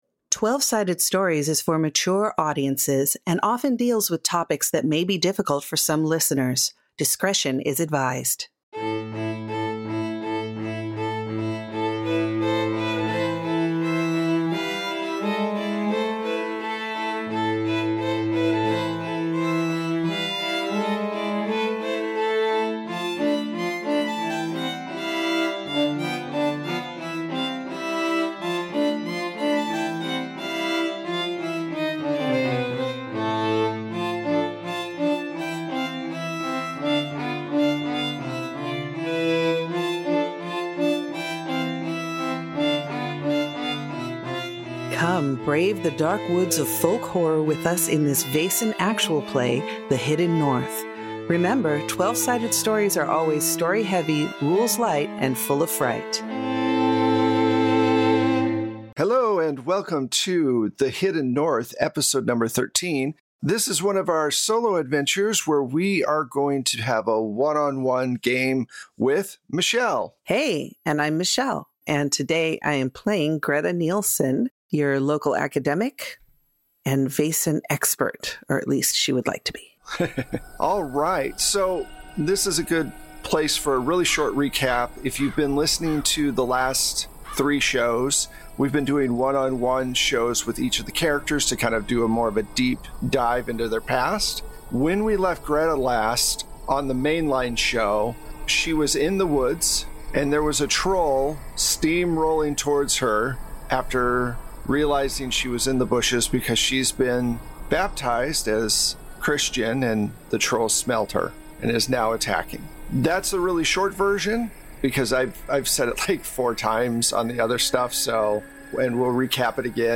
Worlds beyond imagination await you! At Twelve-Sided Stories, we bring tales to life through TTRPGs, with fully produced sound effects and music.